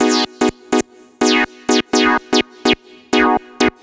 cch_synth_glamour_125_B.wav